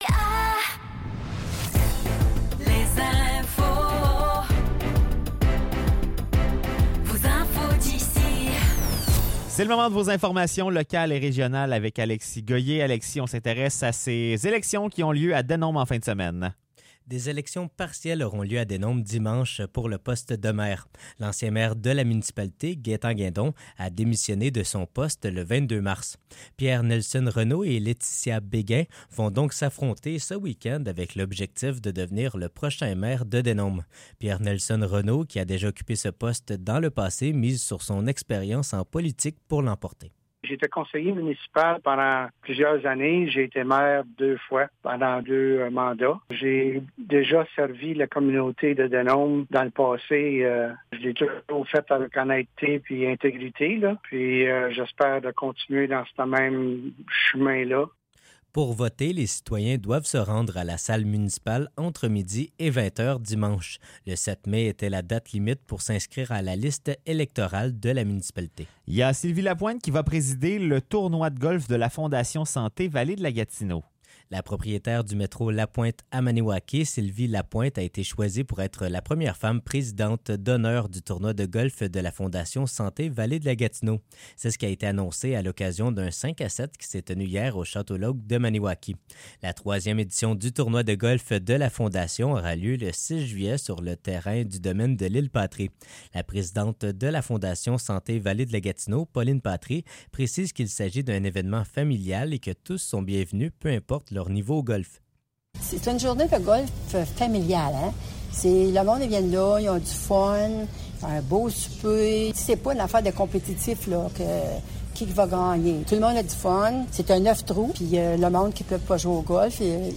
Nouvelles locales - 17 mai 2024 - 16 h